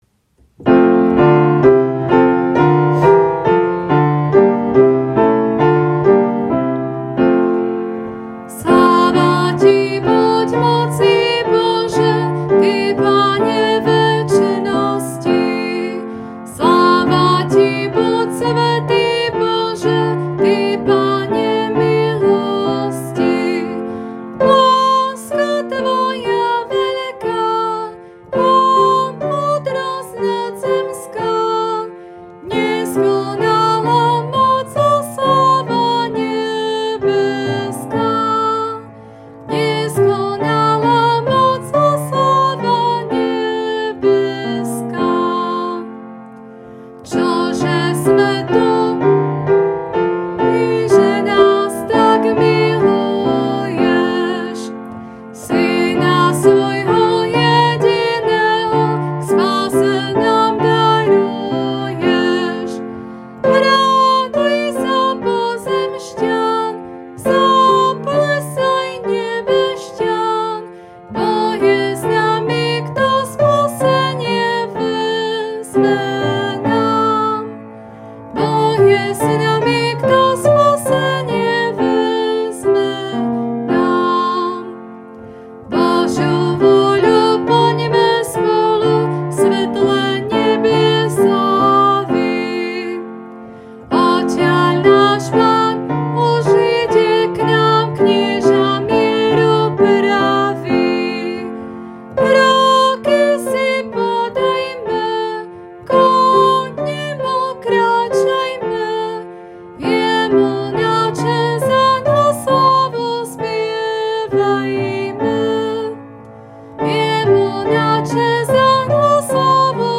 1, Pieseň z Evanjelického spevníka: č.34